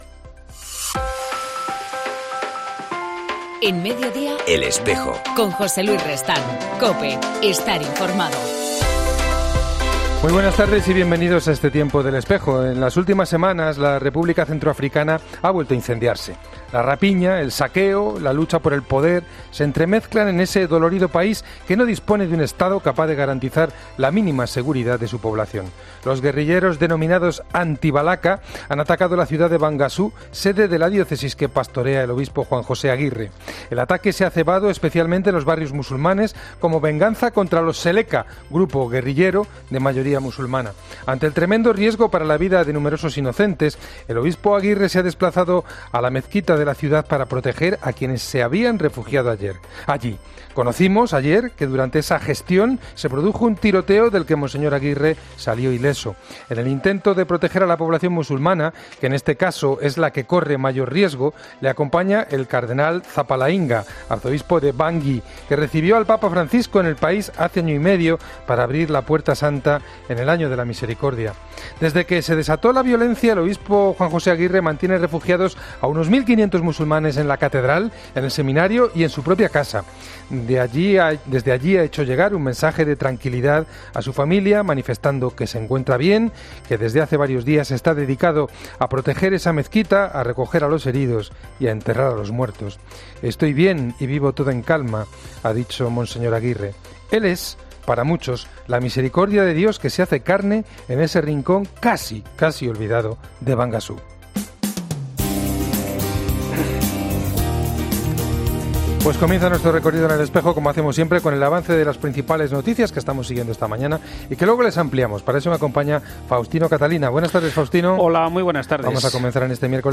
AUDIO: En El Espejo entrevistamos